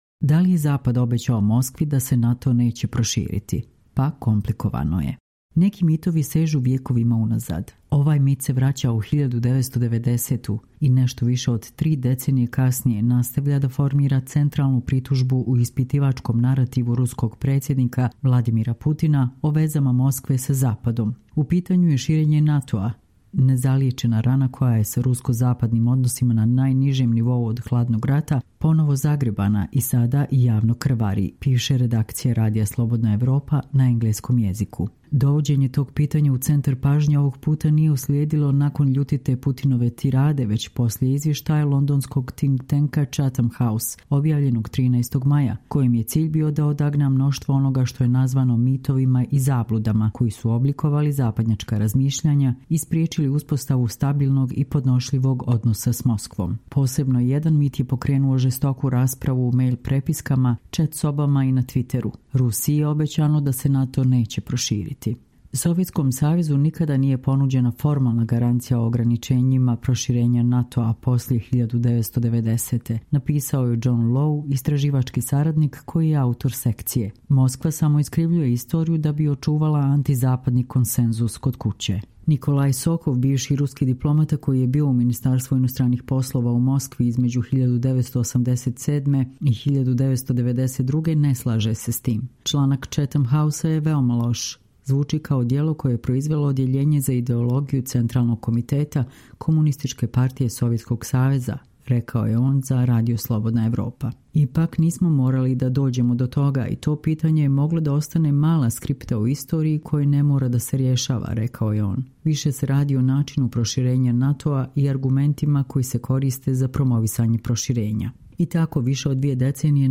Čitamo vam: Da li je Zapad obećao Moskvi da se NATO neće proširiti? Pa, komplikovano je